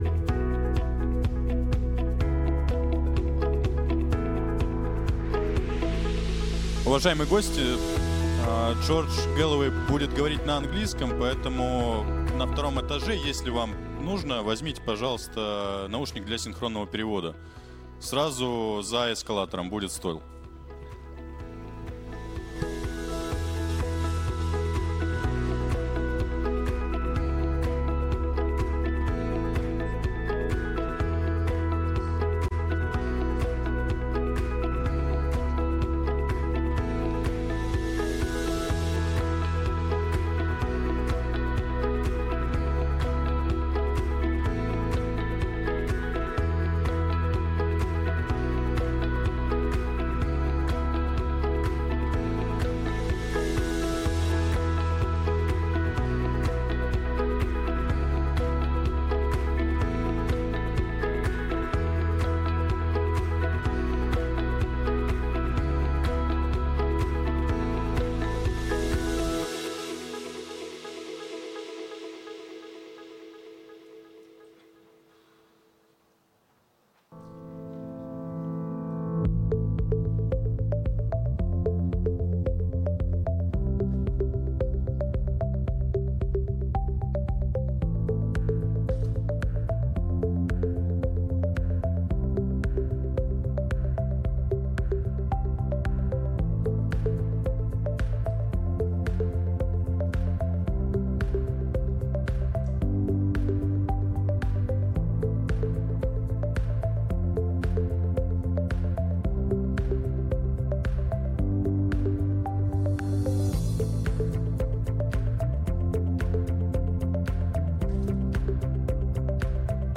Выступление Джорджа Гэллоуэйя. «Форум Будущего 2050»
Приглашенные эксперты и спикеры: Джордж Галлоуэй